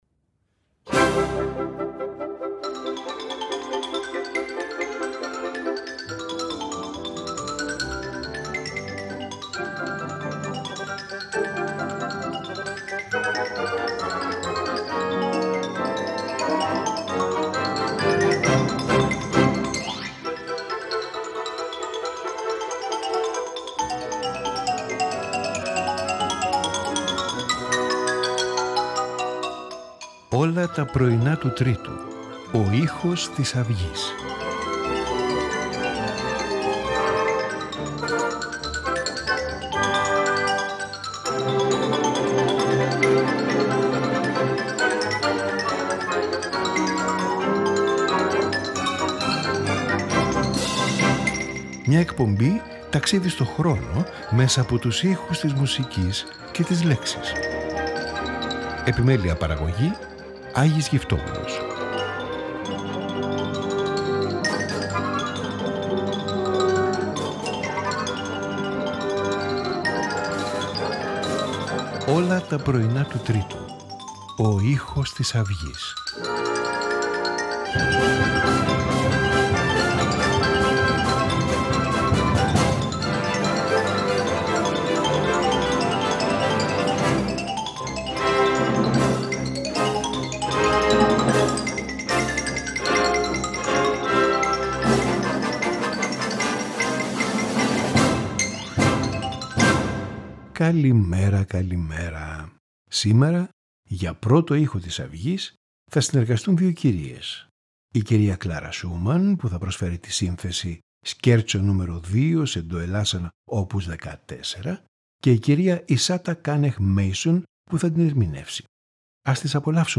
Violin Concerto in G
String Quartet in B-flat
Konzertstück for Oboe and Orchestra in F